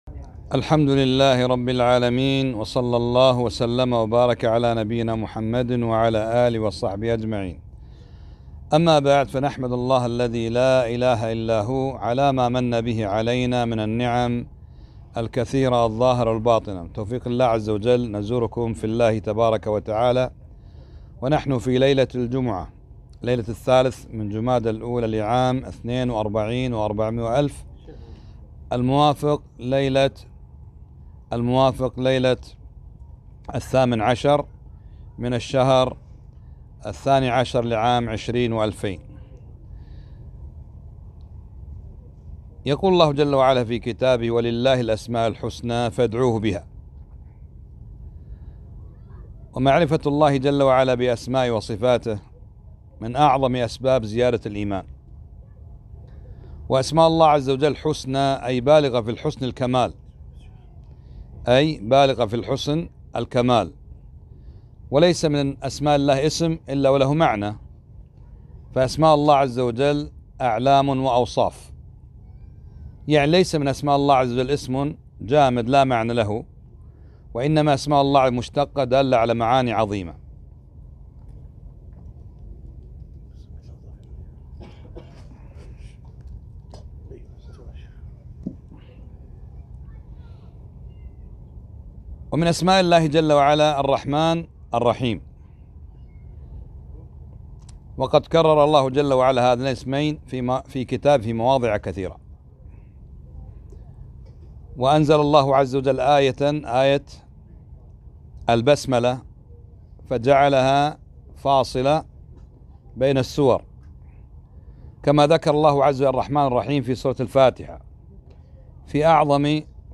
محاضرة - سعة رحمة الله 2-5-1442